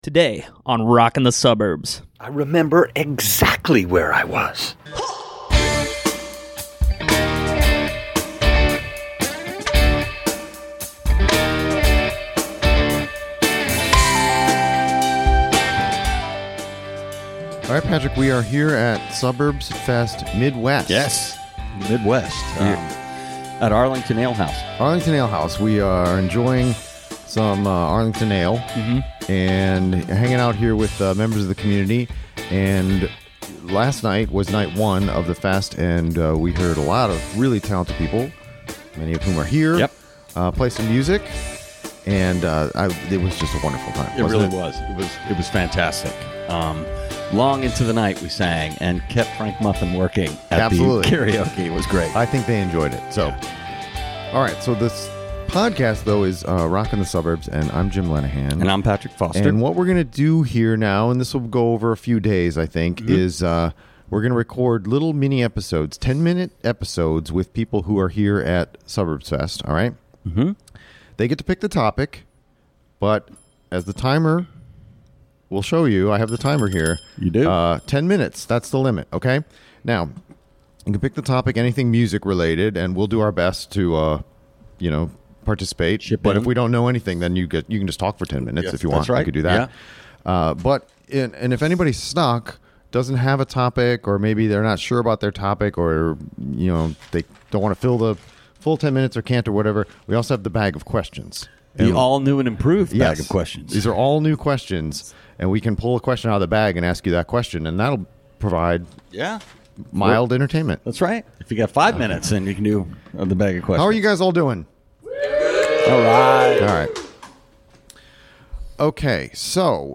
A crowd gathered at Arlington Ale House to join us and contribute.